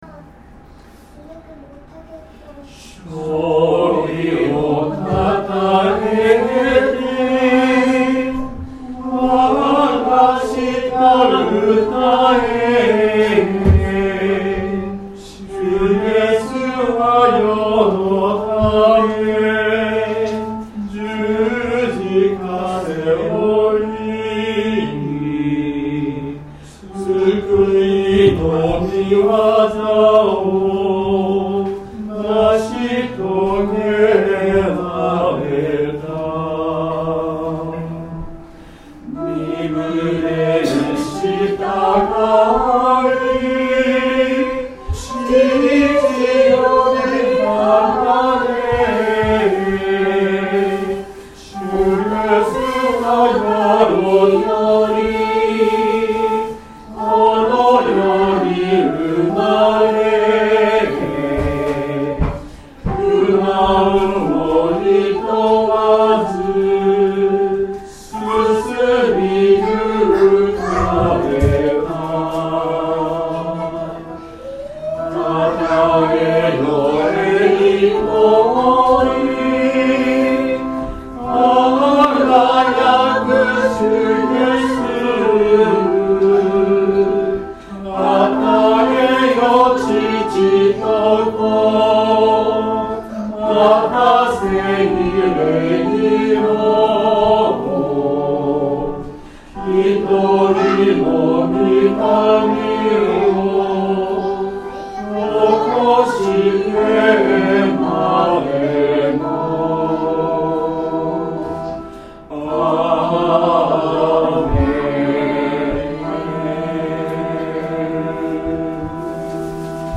曲：単旋律聖歌 PANGE LINGUA
Temperament = Equal